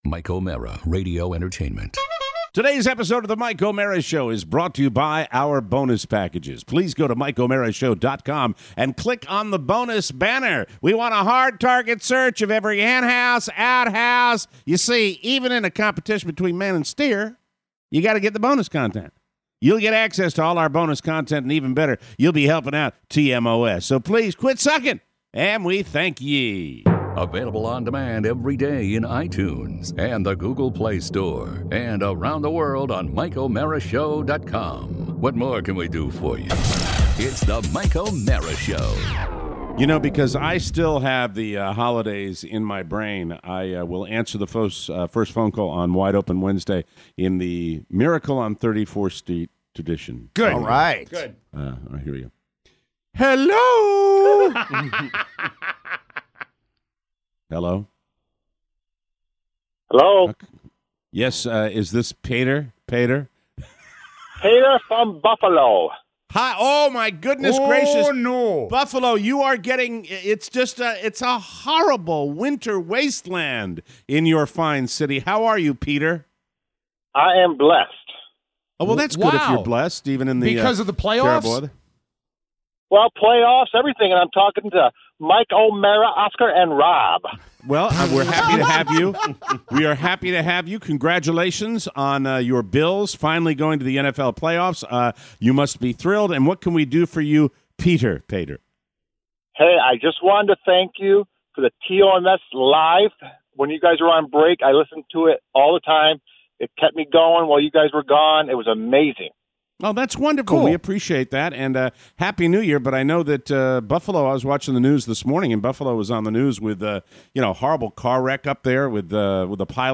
Wide Open Wednesday with your calls! Plus… Iguanaria… John Mayer… Rast Times… gift problems and brand new 2018 impressions!